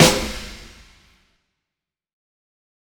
massivclap_livesnr.wav